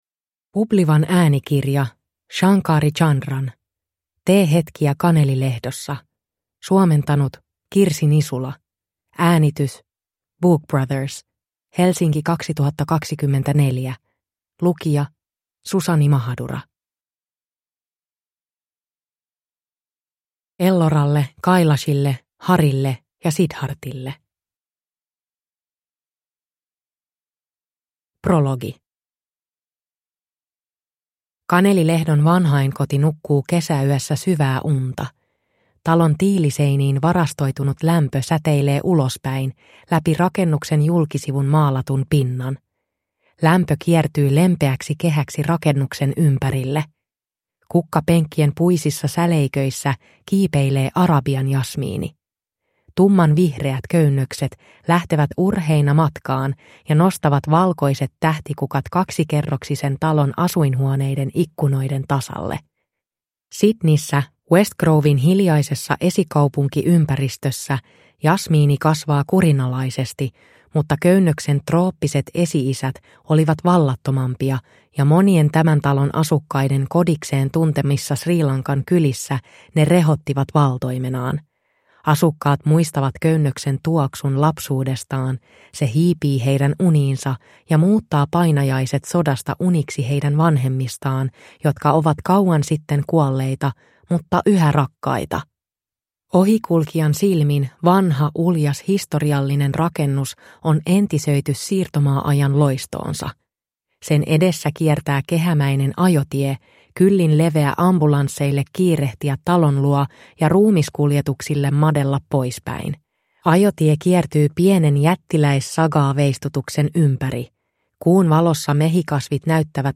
Teehetkiä Kanelilehdossa (ljudbok) av Shankari Chandran